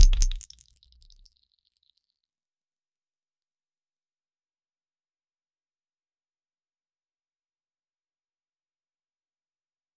heavy Goo squish sound 0:10 A sticky slime ball hits the ground and bounces off but then comes back but this time it dosent bounce ever again. The noise it makes is long lasting 0:10 Gallons of slime flowing through a narrow pipe inside listen 0:10 Sound for a poisonous slime ball shot from a barrel in a game, caused by an enemy’s tilt.
a-sticky-slime-ball-hits-ggusgvor.wav